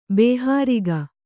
♪ bēhāriga